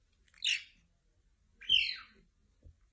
Чирикающий котенок каракала